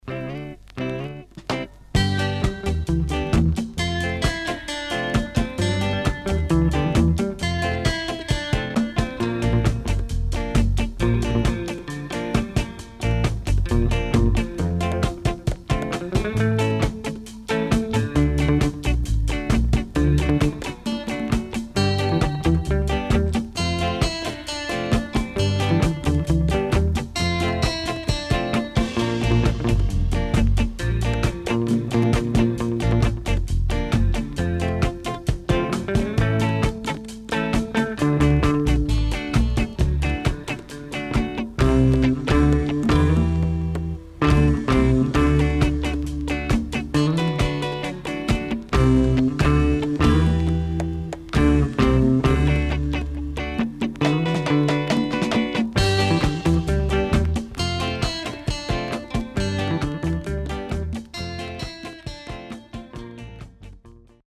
SIDE A:序盤大きめのパチノイズあり、それ以降所々ノイズあります。